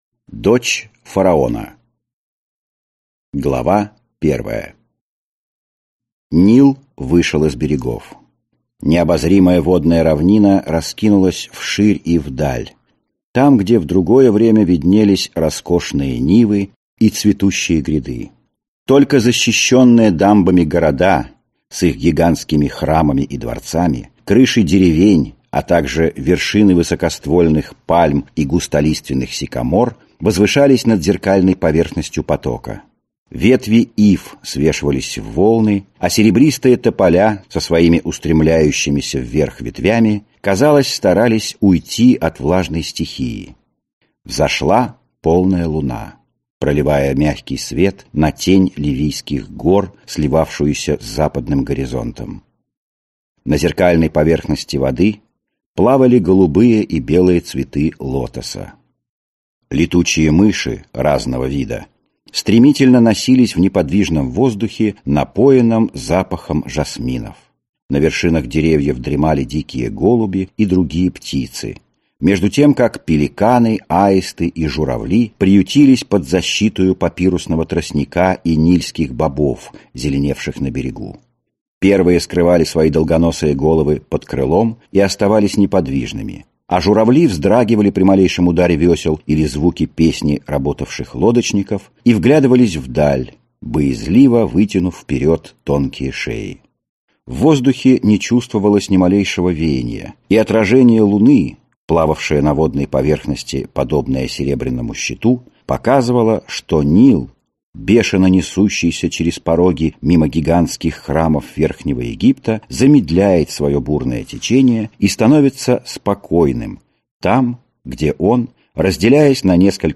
Аудиокнига Дочь фараона | Библиотека аудиокниг